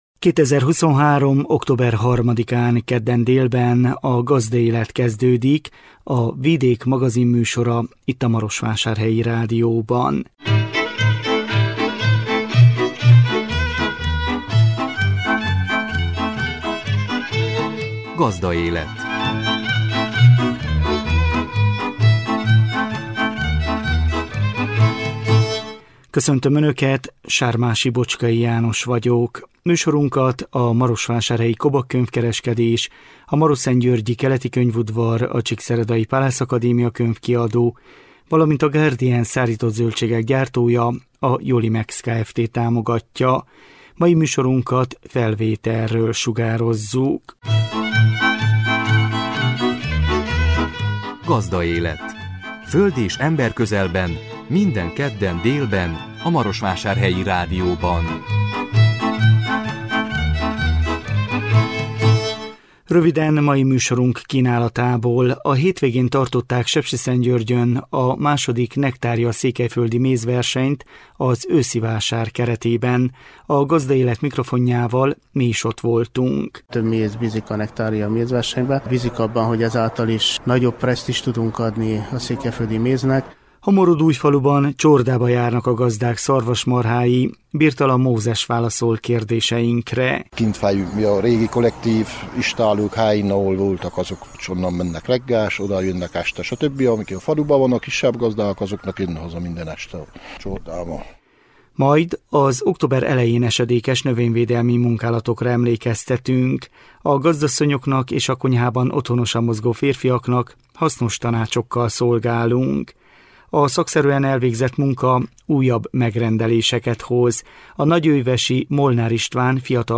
A 2023 október 3-án jelentkező műsor tartalma: A hétvégén tartották Sepsiszentgyörgyön a II. Nektária Székelyföldi Mézversenyt az Őszi vásár keretében. A Gazdaélet mikrofonjával mi is ott voltunk.